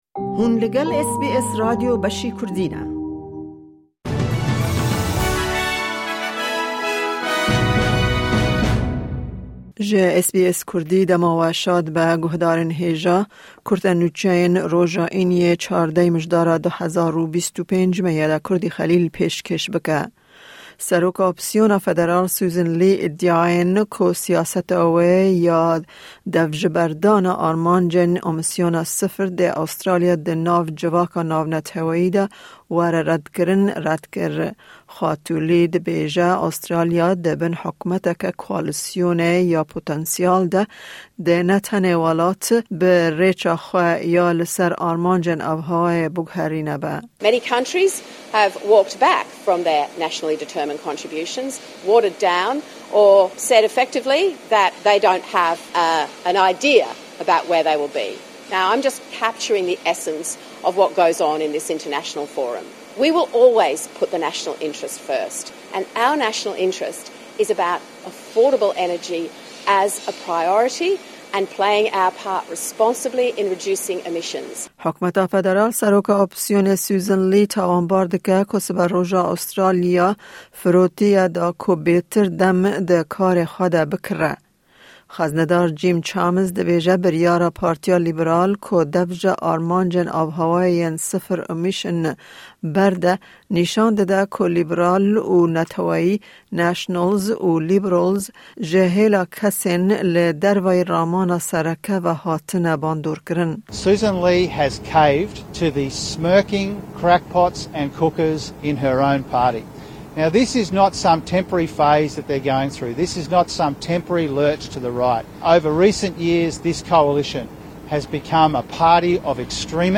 Nûçe